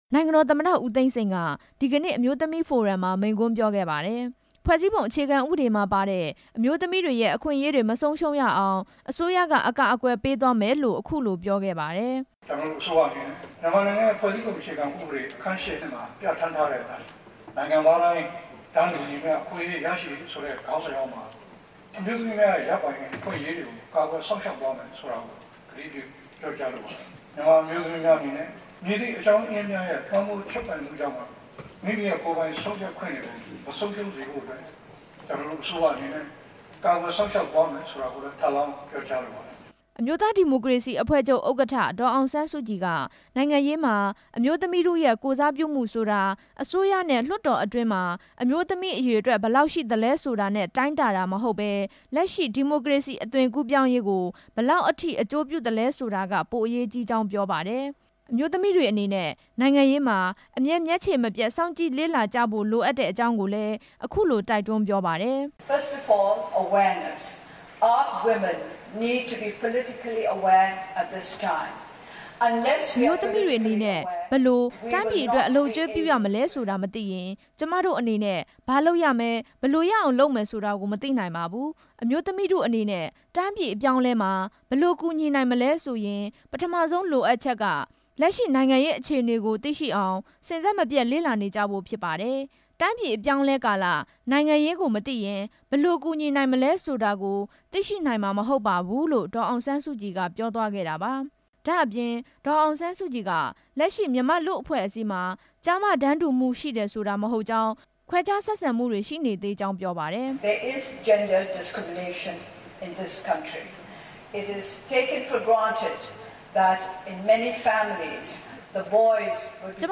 နေပြည်တော် မြန်မာအပြည်ပြည်ဆိုင်ရာ ကွန်ဗင်းရှင်းဗဟိုဌာနမှာကျင်းပတဲ့ ကမ္ဘာလုံးဆိုင်ရာ အမျိုးသမီးဖိုရမ်မှာ ပြောကြားတဲ့မိန့်ခွန်းမှာ ထည့်သွင်းပြောကြားခဲ့တာဖြစ်ပါတယ်။
အဲဒီအခမ်းအနားမှာ နိုင်ငံတော်သမ္မတ ဦးသိန်းစိန်နဲ့ အမျိုးသား ဒီမိုကရေစီအဖွဲ့ချုပ် ဥက္ကဌ ဒေါ်အောင်ဆန်း စုကြည်တို့ ပြောကြားခဲ့တဲ့မိန့်ခွန်းတွေကို